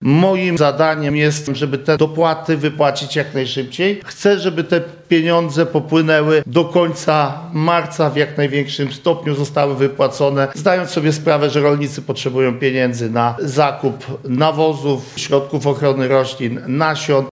Ponad miliard złotych dopłat popłynie 10 stycznia na konta ponad 300 tysięcy rolników – zapewnił wiceminister rolnictwa Stefan Krajewski na konferencji prasowej przed rozpoczęciem Wojewódzkiej Rady Dialogu Rolniczego w Lublinie.